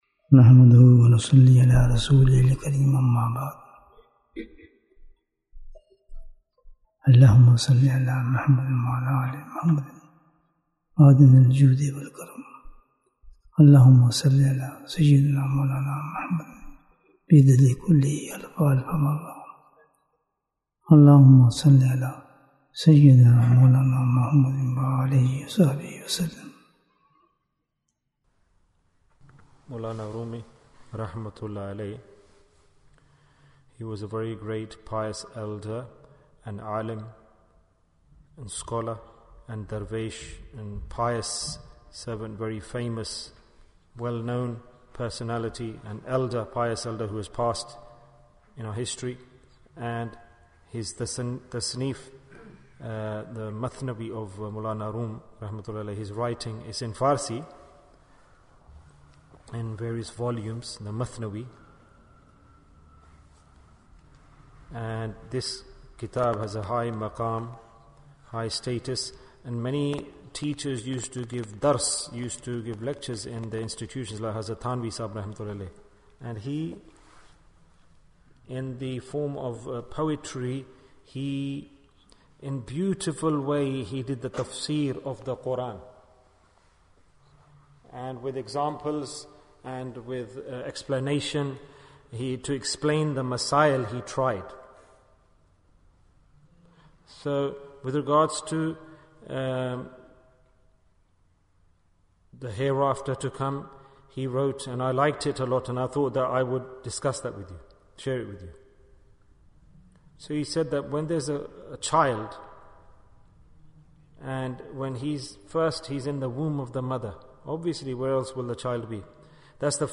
The First Step of the Akhirah & It's Preparation Bayan, 33 minutes11th April, 2023